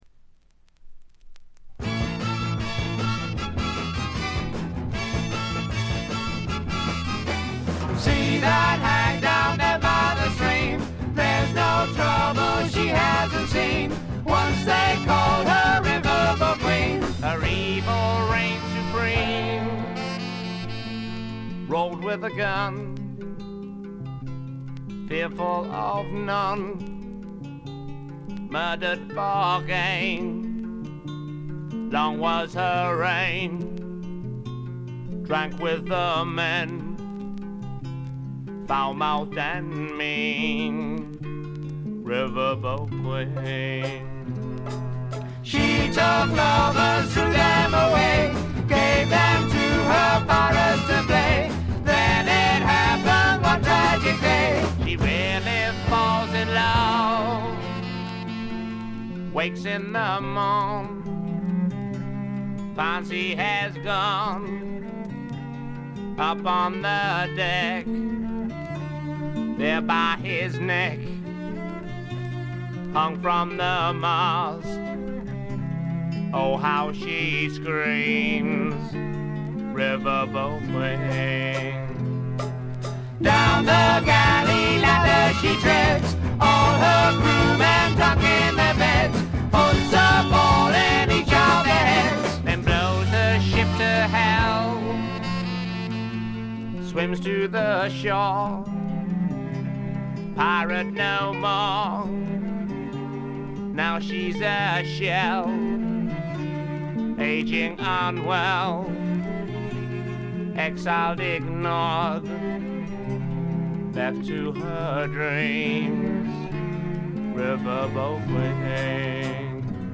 サックスや木管を大胆に導入したアコースティック主体の演奏でジャズ色のある独特のフォーク・ロックを奏でる名作です。
試聴曲は現品からの取り込み音源です。